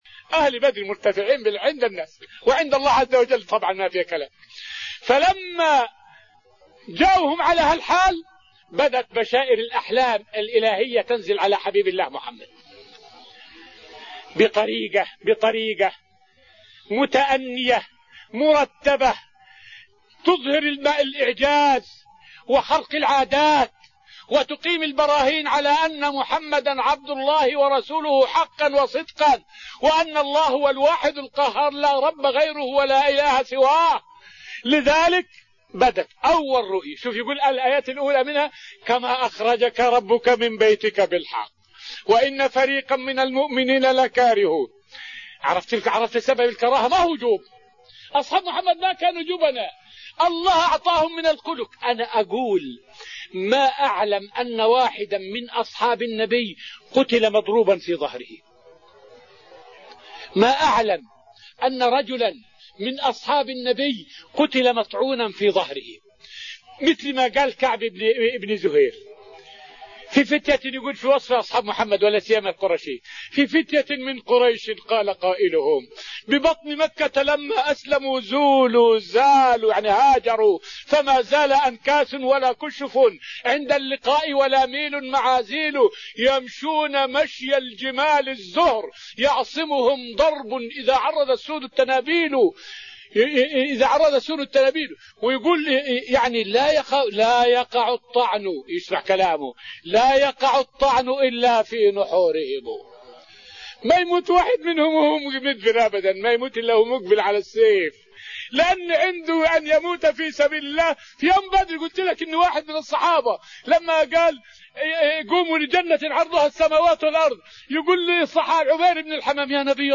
فائدة من الدرس الأول من دروس تفسير سورة الأنفال والتي ألقيت في رحاب المسجد النبوي حول معنى قوله {قل للذين كفروا إن ينتهوا يغفر لهم}.